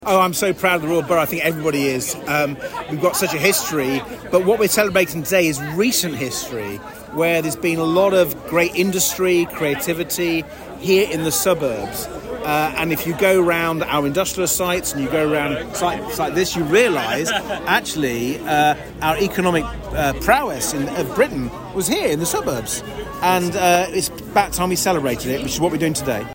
Sir Ed Davey speaks at the Mini Rally